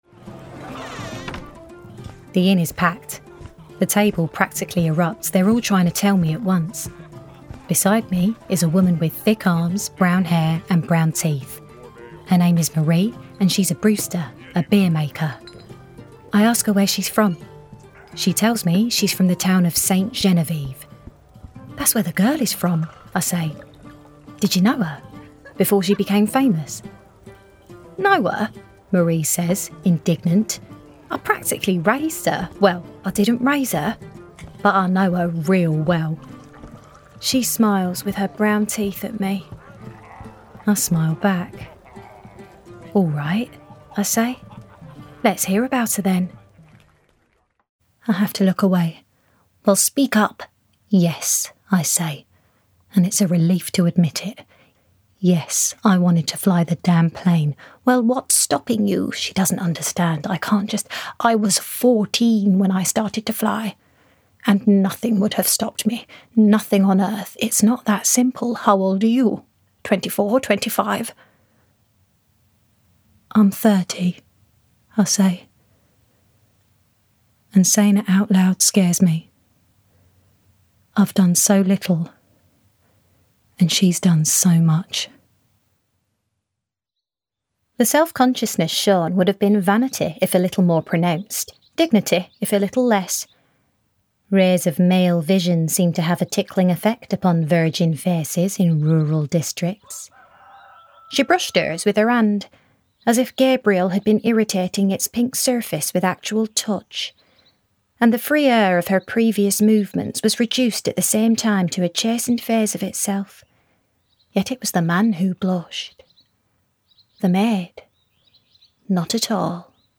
Engaging, Relatable, Current, Versatile